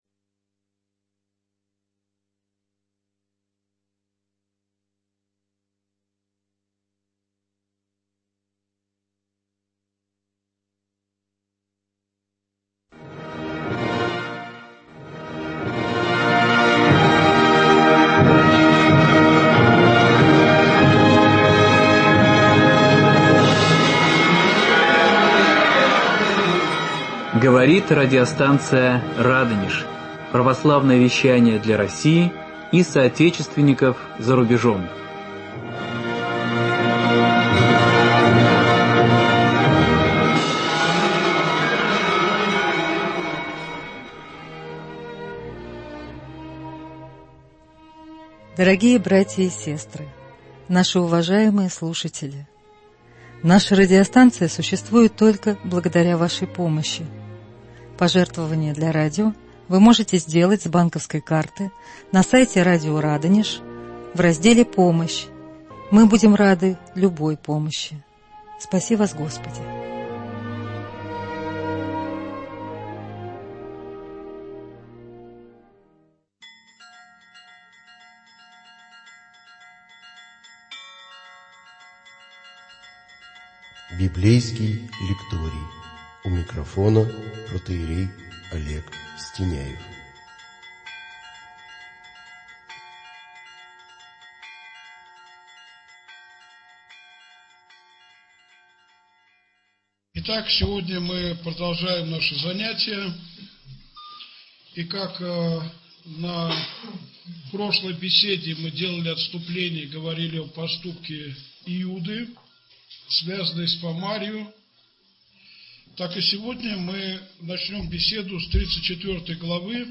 Иосиф Прекрасный и его братья. Проблемы большой семьи. Беседа 3